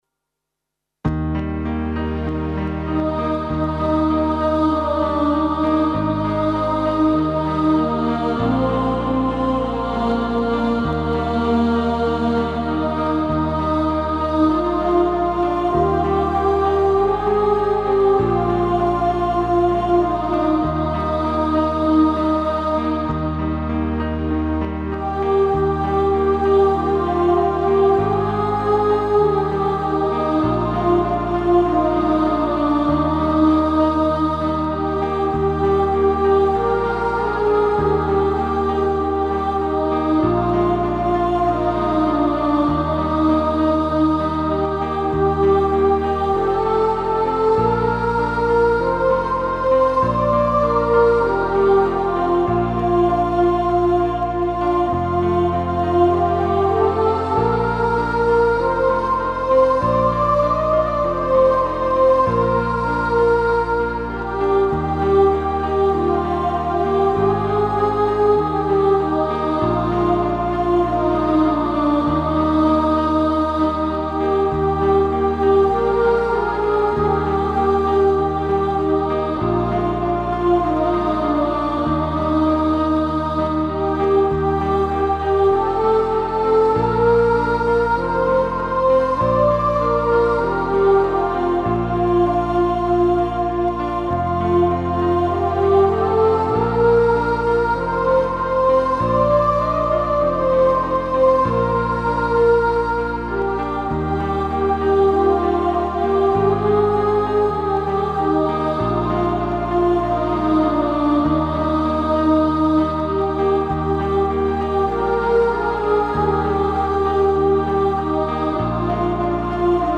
Mota: Gabon Kanta